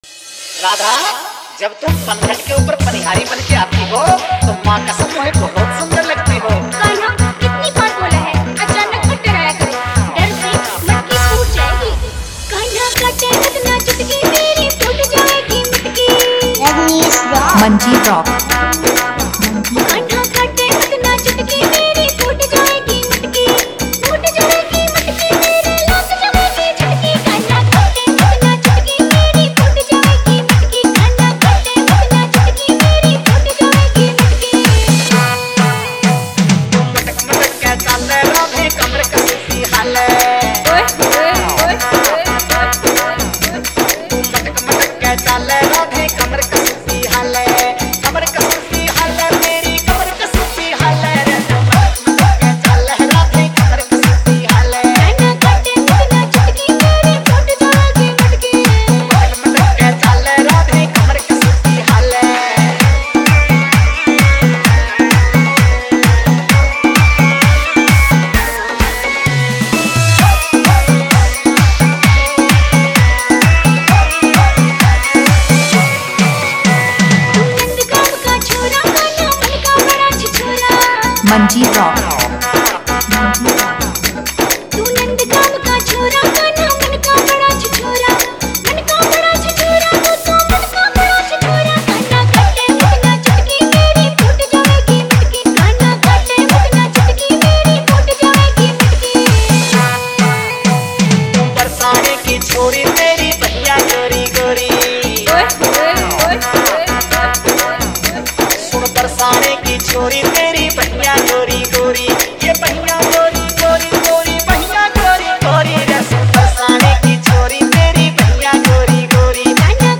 Janmashtami fadu dance remix
Krishna bhakti dance song
Fadu dance mix mp3